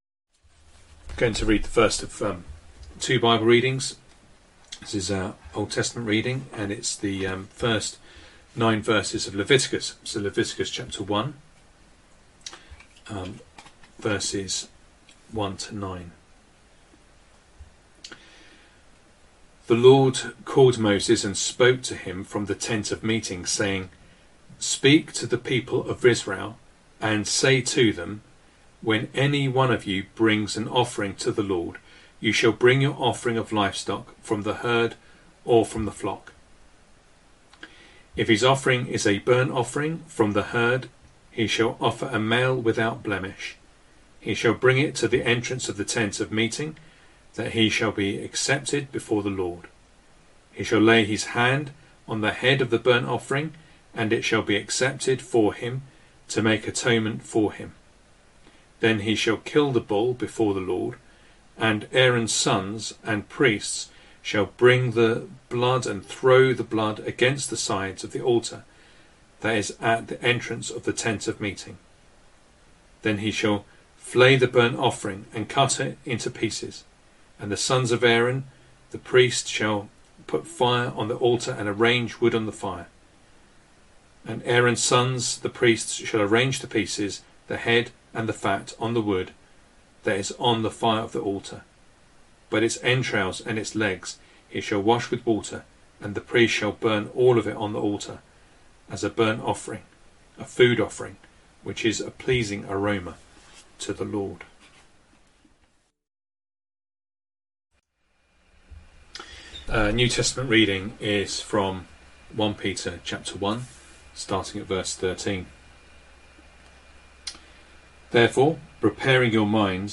Sunday Morning Reading and Sermon Audio